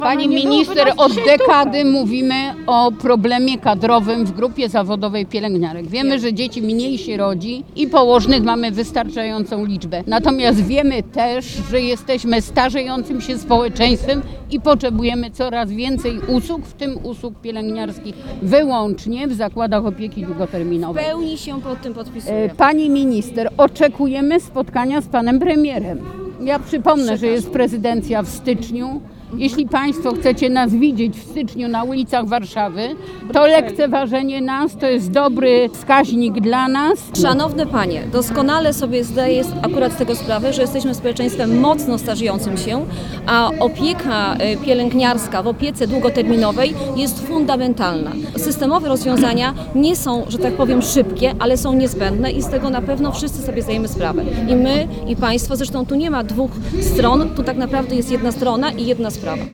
Marzena Okła-Drewnowicz, minister do spraw polityki senioralnej odebrała od delegacji związkowej petycję skierowaną do premiera Donalda Tuska.
obrazek-2-przekazanie-petycji.mp3